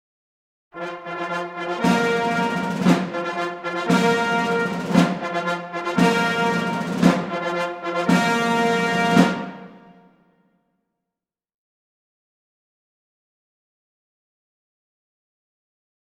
Ruffles are played on drums, and flourishes are played on bugles. They are sounded together, once for each star of the general officer being honored or according to title or office held by the honoree.
Hear the United States Army Band, "Pershing's Own," play four ruffles and flourishes
four_ruffles_and_flourishes.mp3